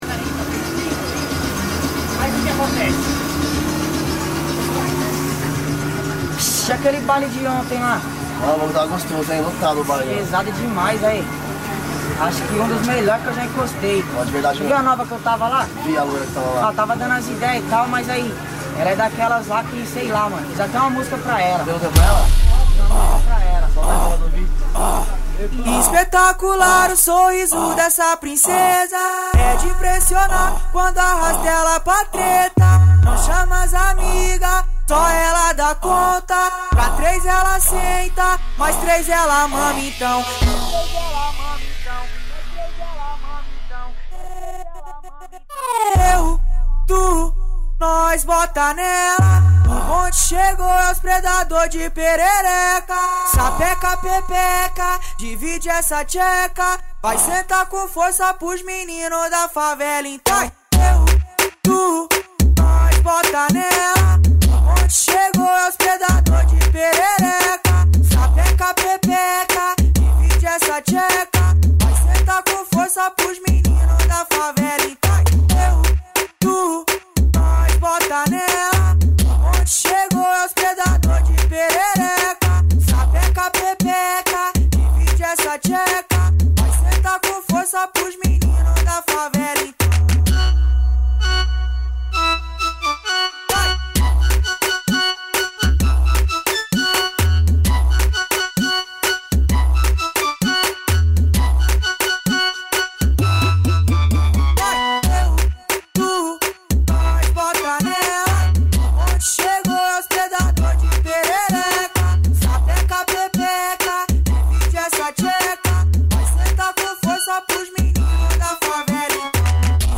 2024-03-31 20:15:21 Gênero: Funk Views